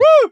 woo.wav